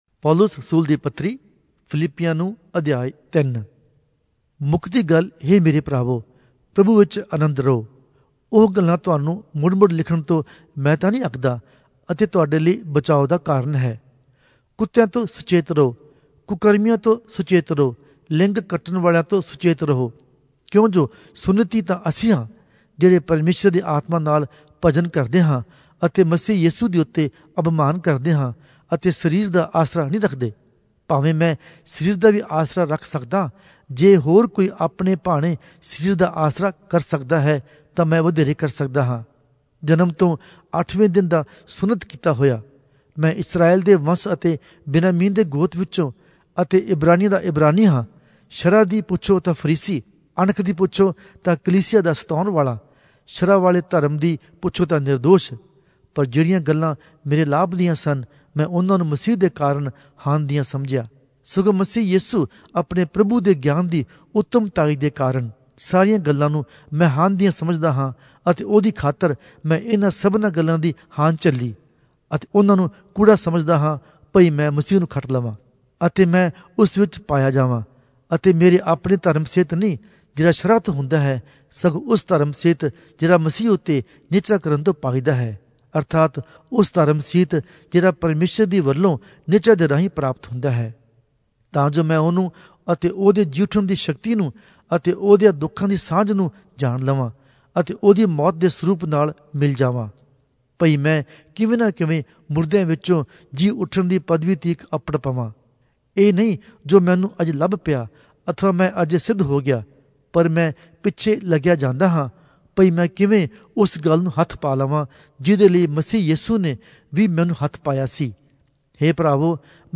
Punjabi Audio Bible - Philippians 3 in Esv bible version